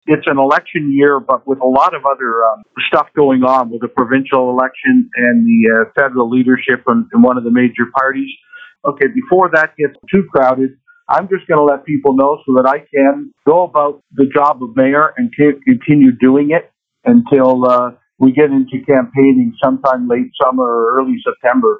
In a conversation with myFM, Preston explained the timing of today’s announcement.